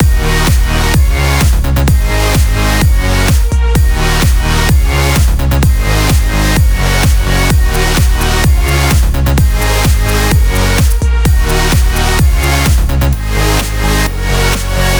VDE 128BPM Close Mix.wav